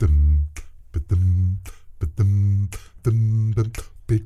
ACCAPELLA 7A.wav